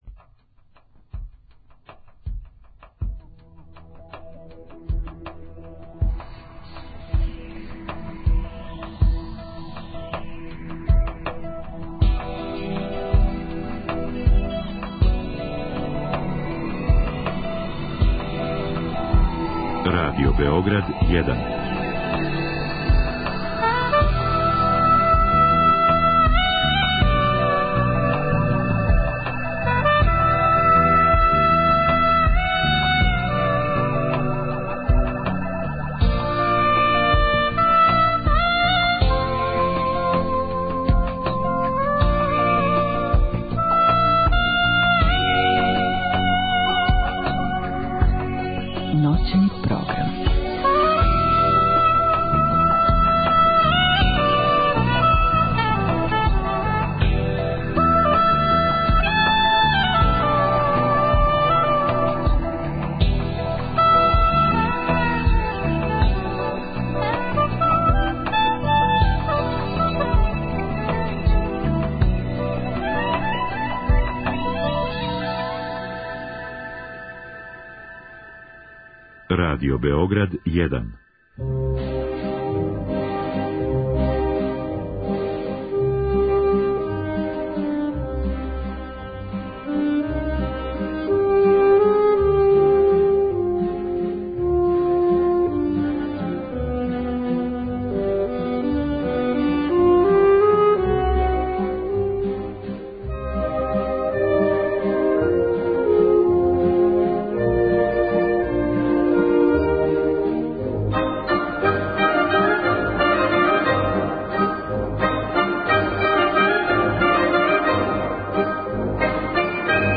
У првом сату Ноћног програма Радио Београда 1 чућете репортажу са XI Интернационалног фестивала класичне музике 'Врњци'. У другом сату емитоваћемо снимке са концерта који је, у оквиру исте манифестације, 22. јула одржао македонски трио 'Ad libitum'.